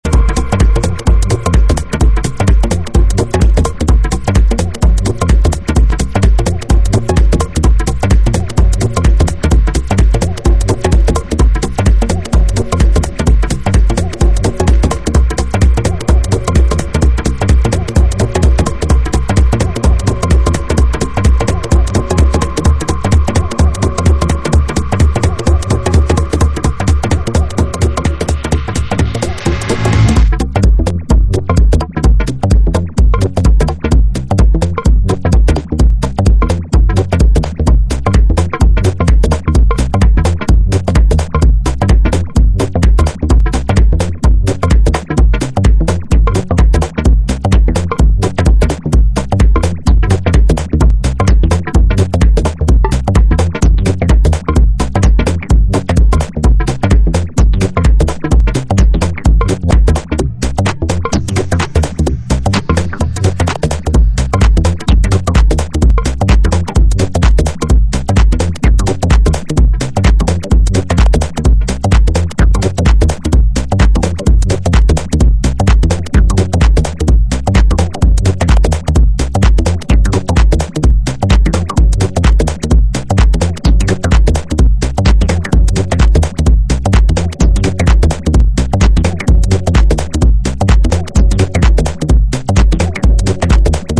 Top techno stuff from Rotterdam!
Techno